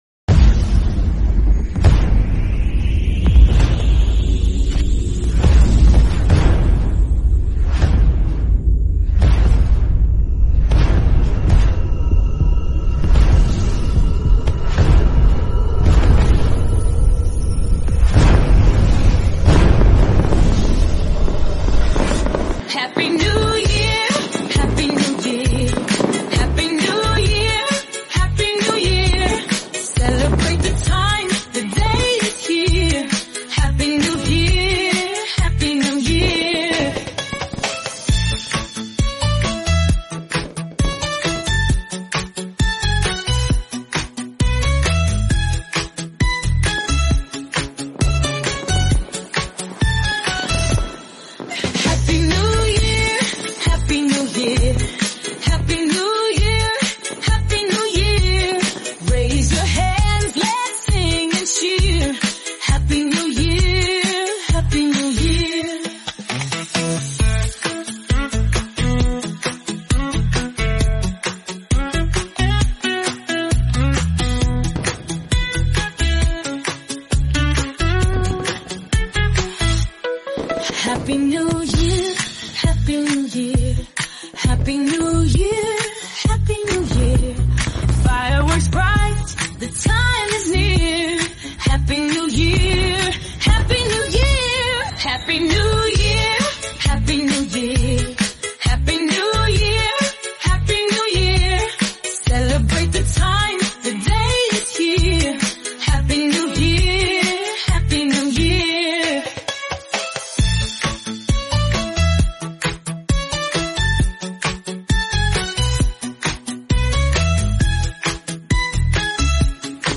New Year Party Music
Festive Holiday Music Party Songs 2025